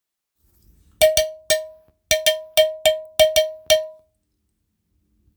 鉄ゴングシングル(CAMEROUN)
手作りの鉄楽器です。鉄製の深い響きが特徴です。
素材： 鉄